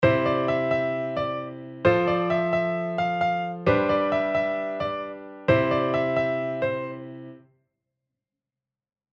前回の記事（⇒こちら）で作ったメロディーを、もっとも基本的なコード進行であるC⇒F⇒G⇒Cに乗せてみます。
※一か所だけ変に聞こますが、後で解説します。
今回の例ではコードG部分の一音目Cが露骨に調子っぱずれに聞こえるので、音CをコードGの構成音である音Bに変えるなどすると、自然に聞こえます。
cfgcmelody.mp3